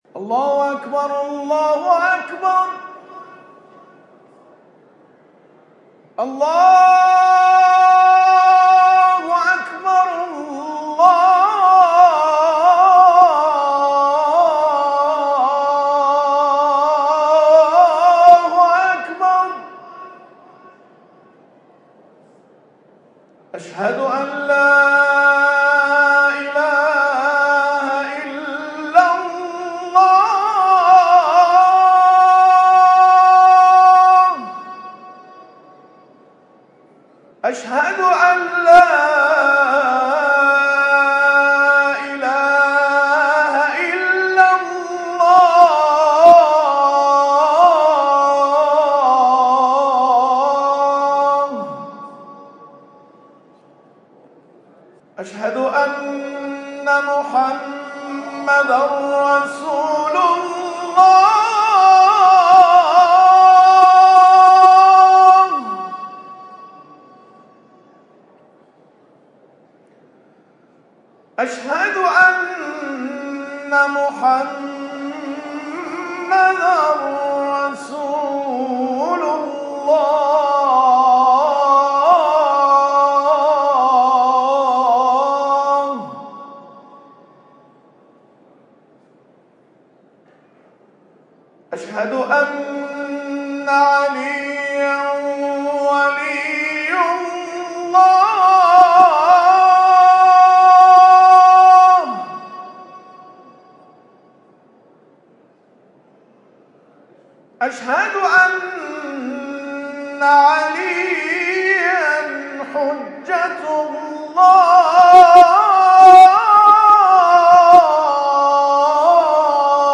تلاوت اذان